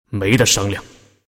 男人说没得商量音效_人物音效音效配乐_免费素材下载_提案神器